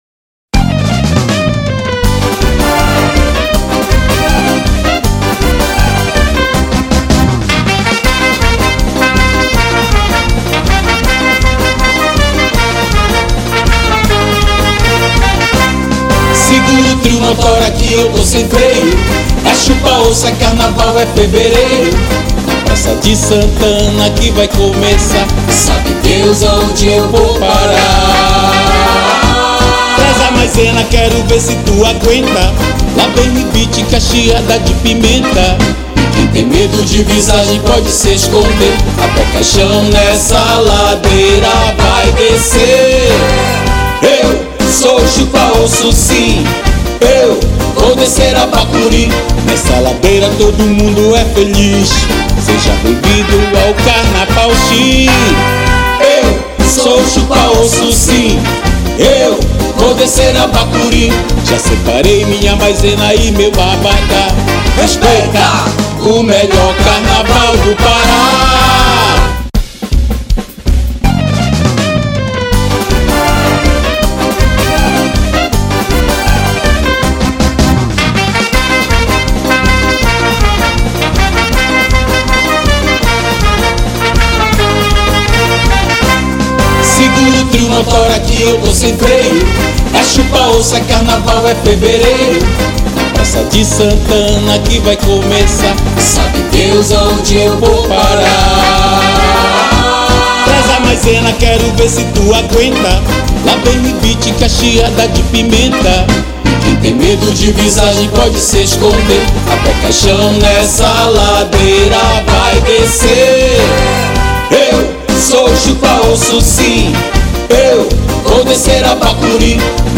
Metais, sopros e demais instrumentos